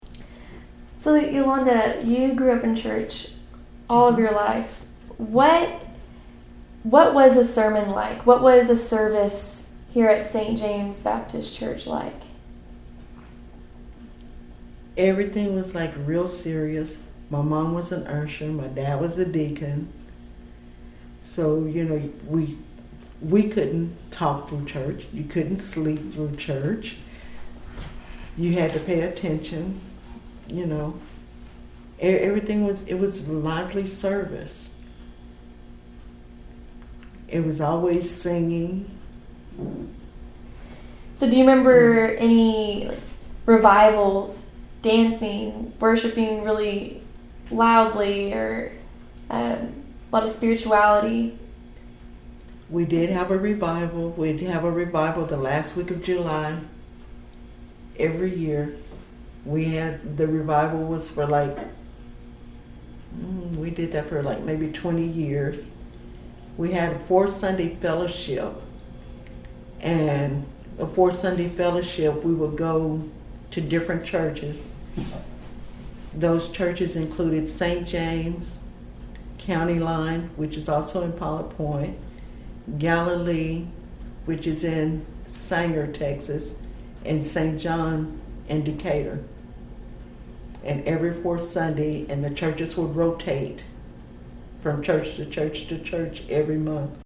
Oral History Interview.